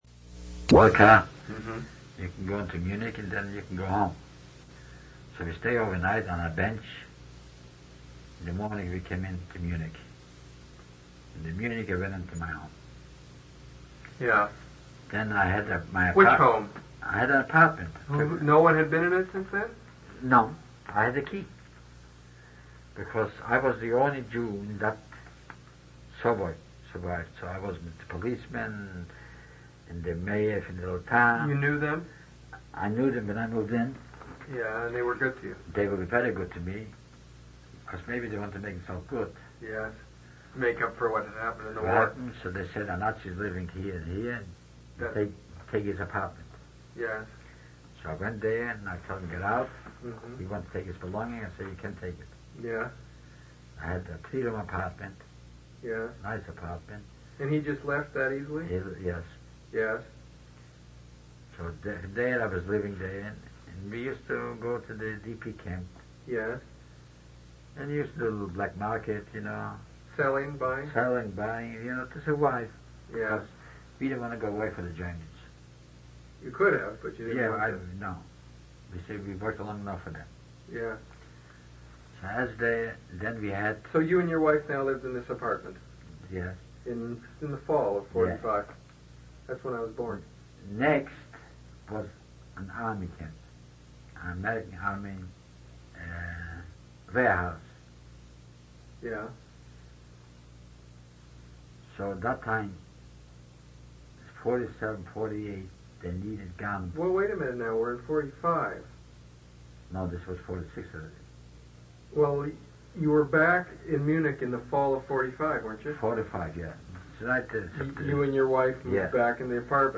Interview took place on November 28, 1980.